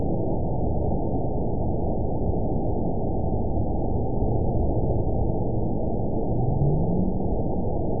event 922853 date 04/21/25 time 05:22:32 GMT (7 months, 2 weeks ago) score 9.50 location TSS-AB02 detected by nrw target species NRW annotations +NRW Spectrogram: Frequency (kHz) vs. Time (s) audio not available .wav